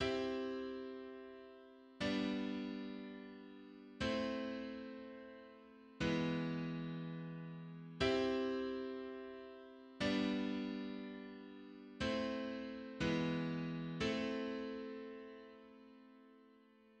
Passamezzo and Romanesca melodic formula[2] on D Play.
A romanesca is composed of a sequence of four chords with a simple, repeating bass, which provide the groundwork for variations and improvisation.
IIIVIIiV—III—VII—i-V—i
Romanesca.mid.mp3